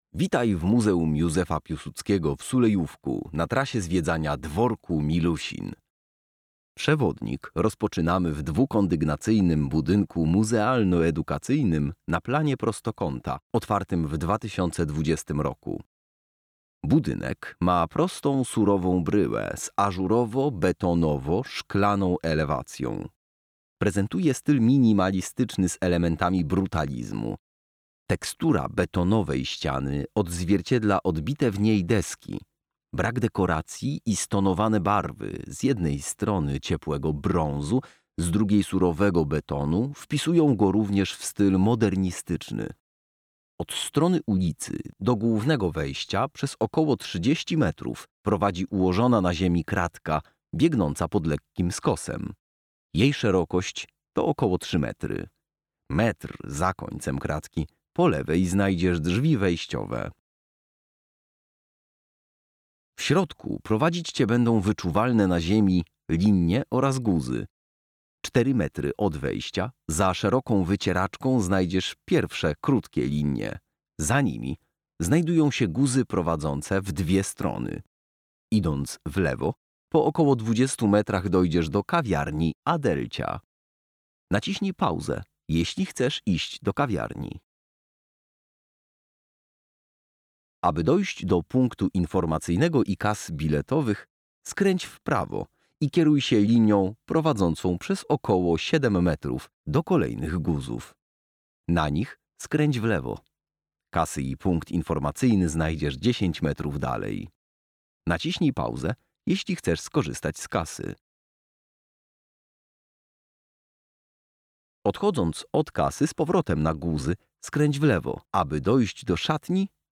Pobierz audiodeskrypcję ścieżki zwiedzania dworku – zawiera ona ogólny opis budynku głównego, szlaków komunikacyjnych oraz wygląd dworku i pomieszczeń w środku.